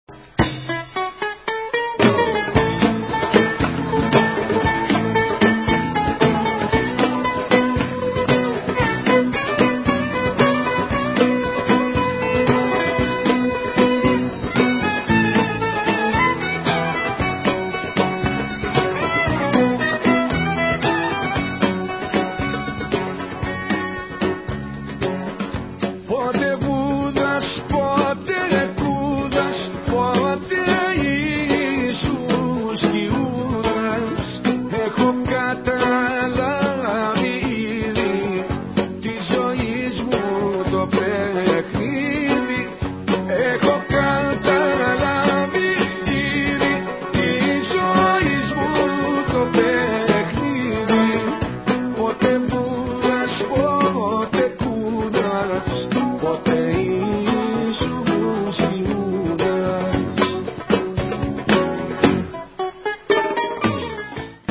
Traditional Greek Music.